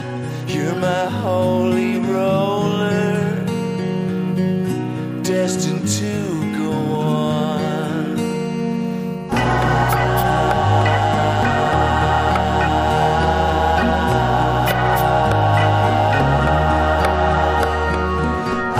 A healthy handful of chords, tautly played.